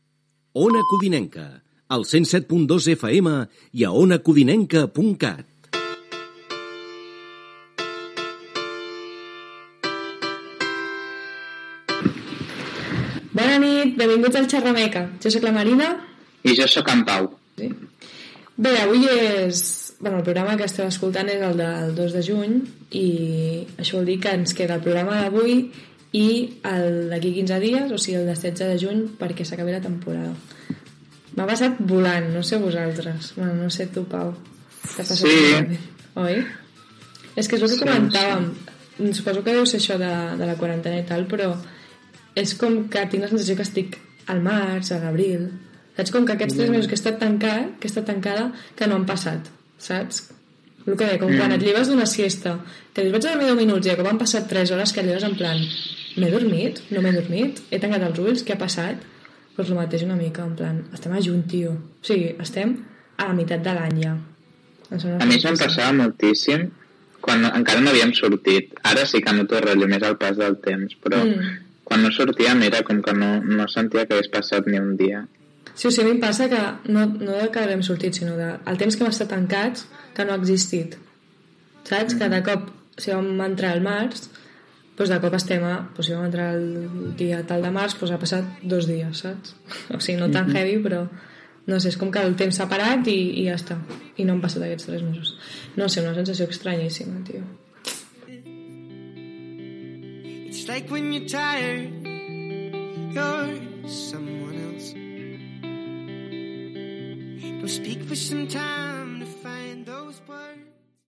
Infantil-juvenil